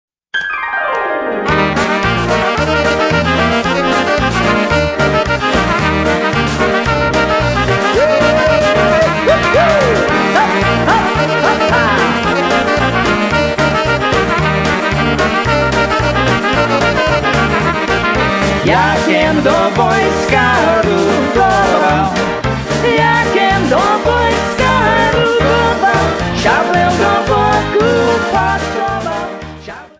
Polkas, Waltzes and Obereks